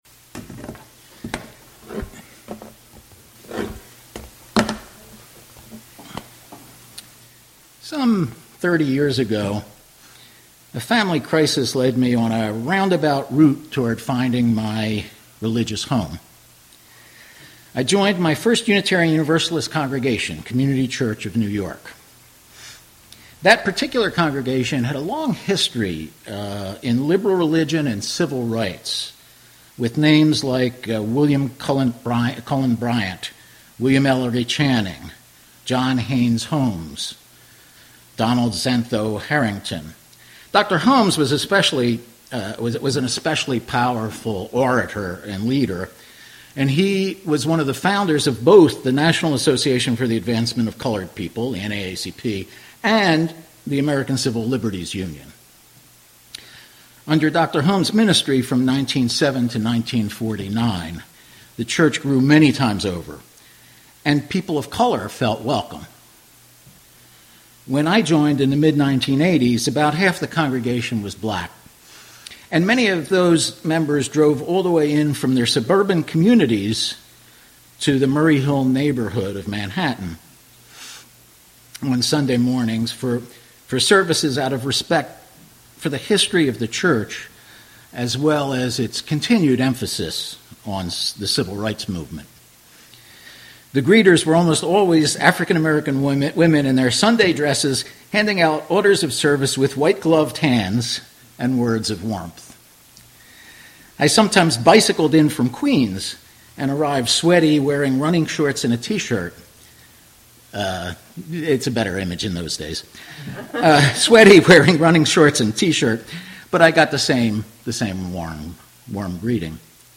This sermon presents a speaker reflecting on their personal journey with Unitarian Universalism, particularly through the lens of socially responsible investing and the speaker’s own contrari…